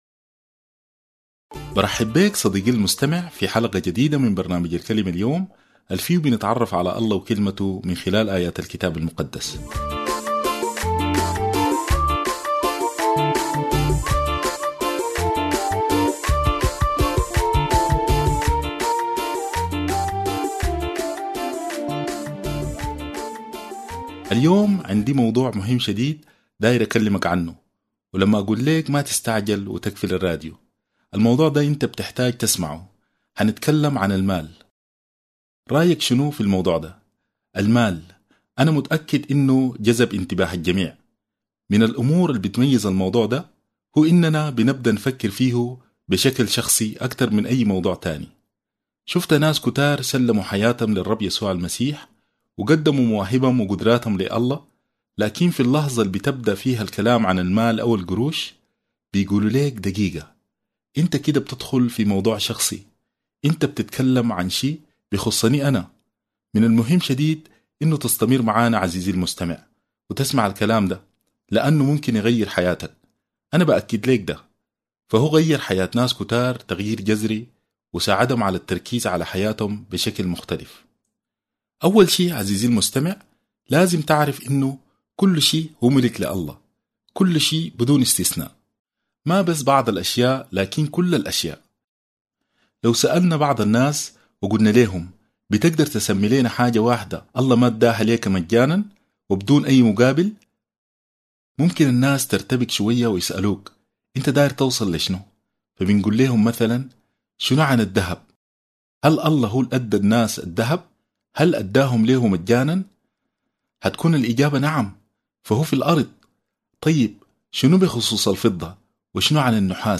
الكلمة اليوم باللهجة السودانية